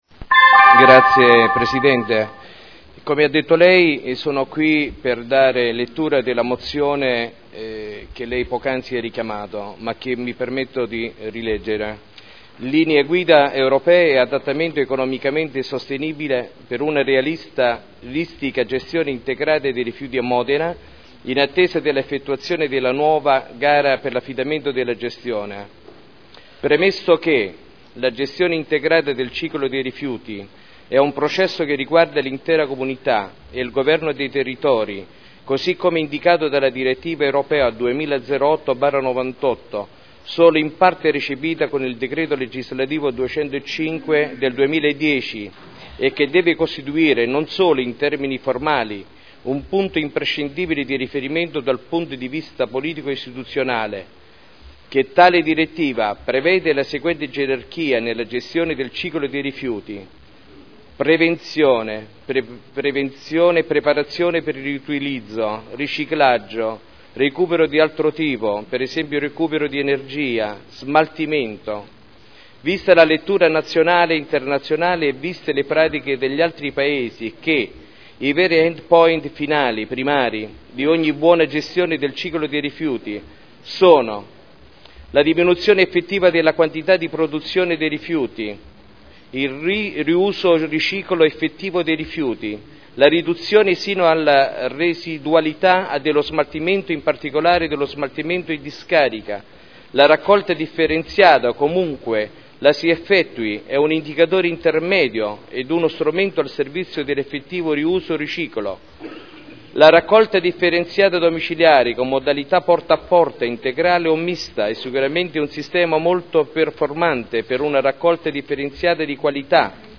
Maurizio Dori — Sito Audio Consiglio Comunale